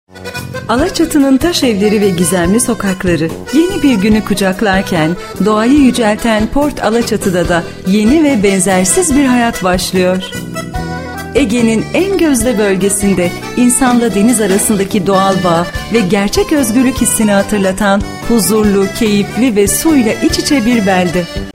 Locución de película promocional